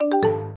newmessage.mp3